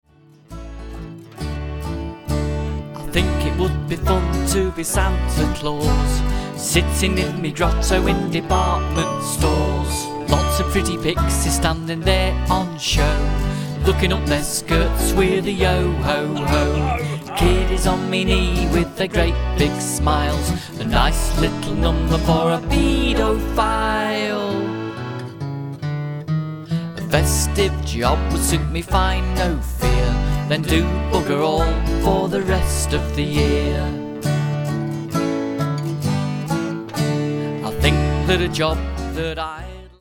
--Comedy Music